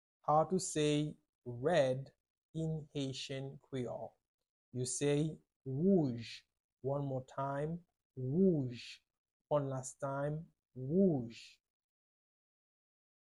16.How-to-say-Red-in-Haitian-Creole-wouj-with-Pronunciation.mp3